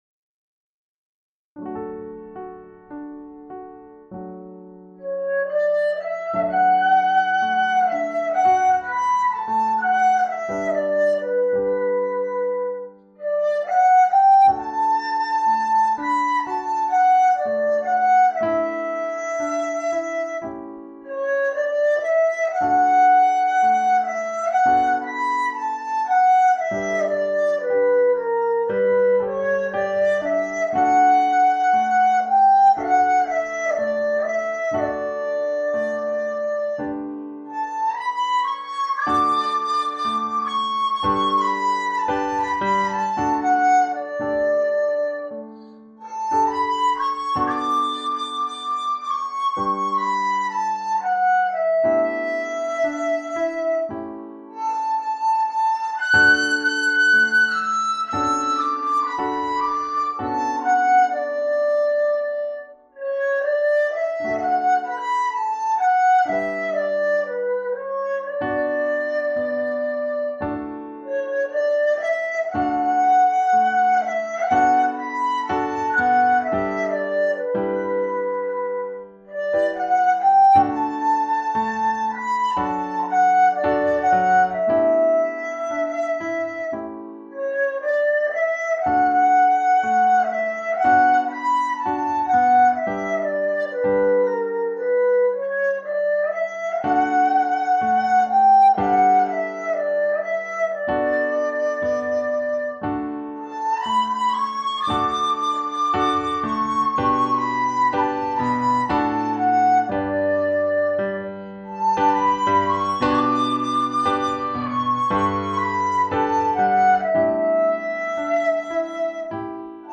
太忙，录得比较粗糙，凑合听吧：)）祝大家春天快乐！
在散漫的钢伴下尽显孤独和凄凉！
笛声悠扬，精彩！
令人情长思悠。笛声切，琴声浓。。。
舒缓悠扬，迷人！这样的琴笛二重奏，听的能让人心立刻静下来，